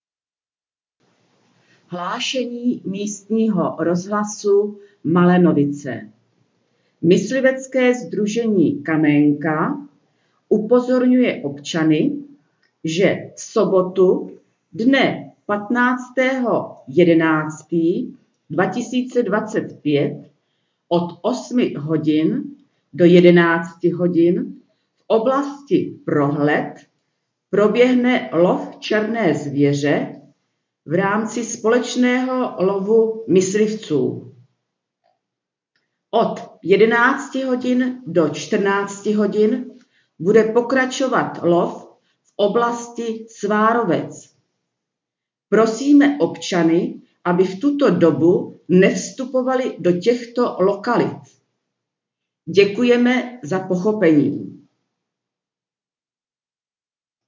Hlášení místního rozhlasu
Hlášení ze dne 12.11.2025